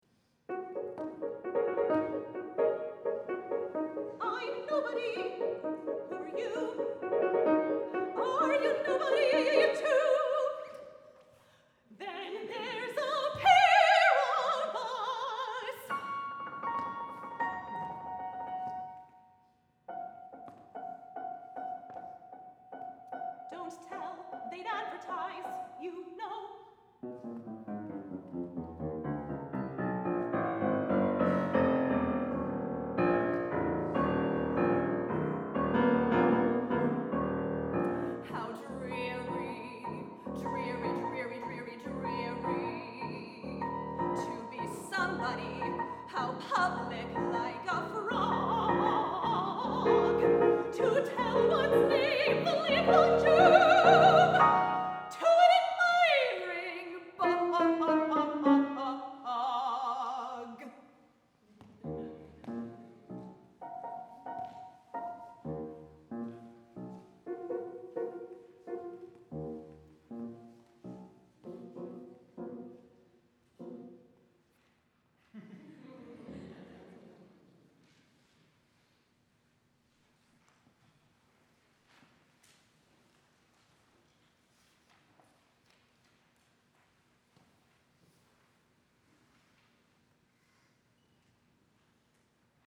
for Soprano and Piano (2014)